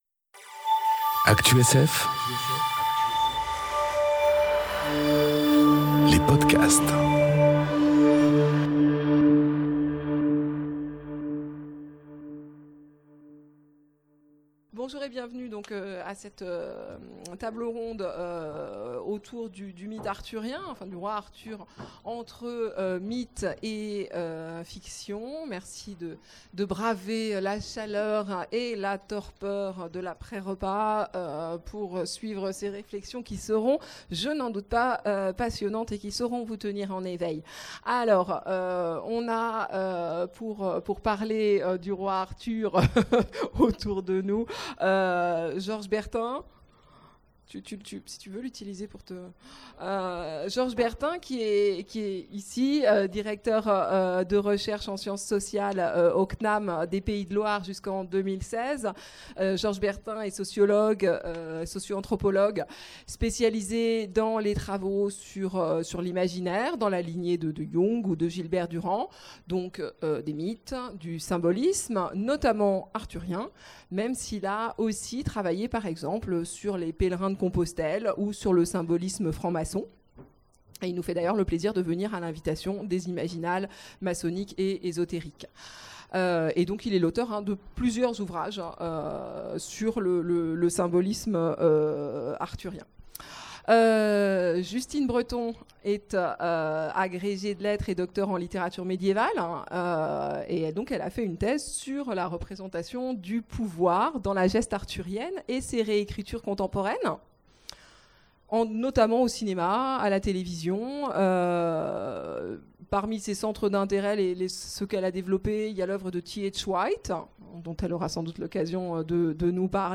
Conférence Le mythe arthurien : entre histoire et fiction... enregistrée aux Imaginales 2018